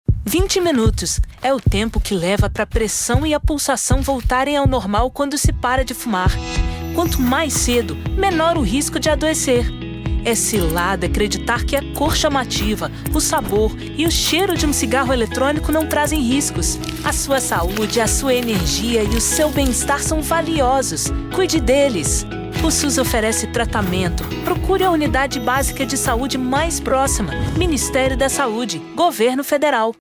Spots